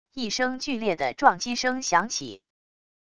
一声剧烈的撞击声响起wav音频